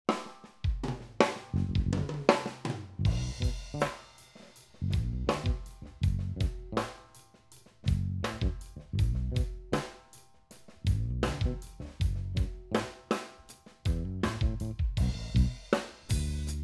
Verbtone – Physical modeled plate reverb – Smooth and deep
Drums_Dry
Verbtone_DrumMix_Dry.wav